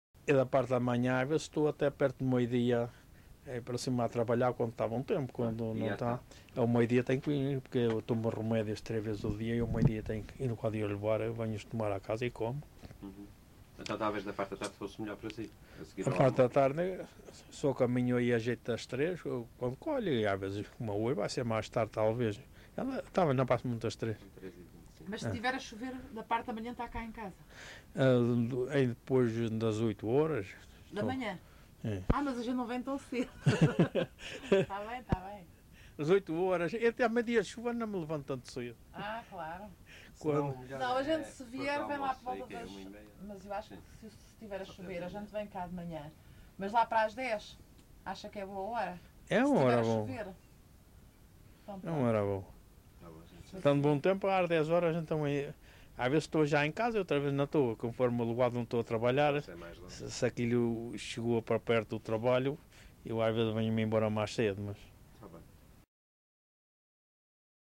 LocalidadeRibeira Seca (Calheta, Angra do Heroísmo)